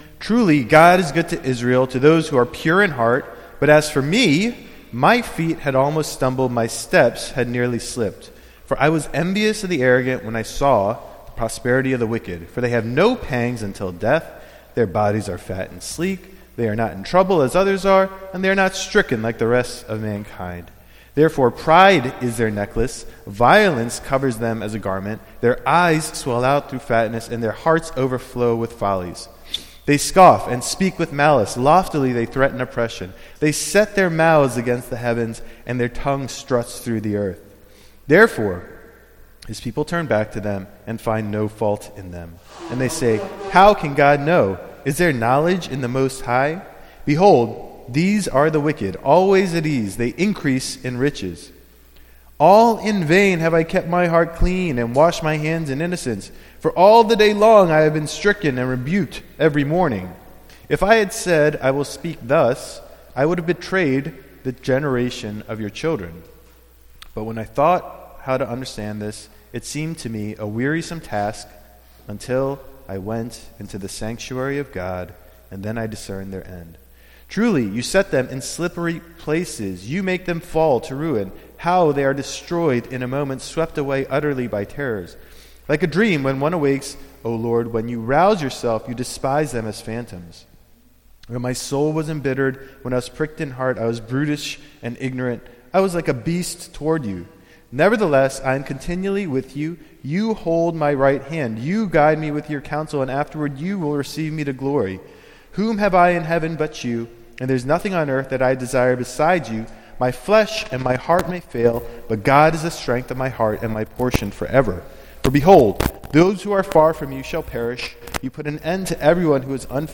Weekly sermons from GNCNYC